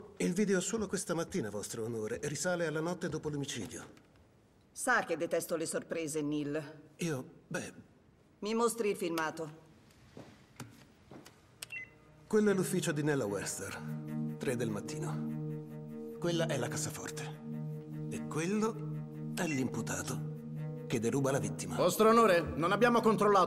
nel telefilm "Bull"